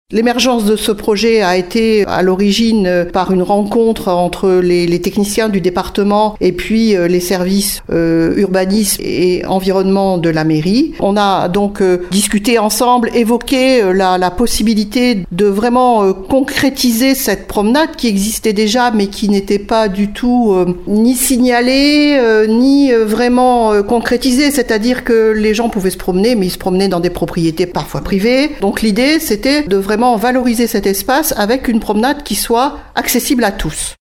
Un deuxième tronçon est à présent rendu accessible, et il étend le sentier pédestre le long de la Gères jusqu’à la rocade Ouest. On écoute Marie-Joëlle Lozach-Salaün, adjointe au maire en charge de l’environnement :